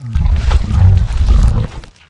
flesh_eat_4.ogg